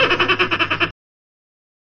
جلوه های صوتی
دانلود صدای دلفین 5 از ساعد نیوز با لینک مستقیم و کیفیت بالا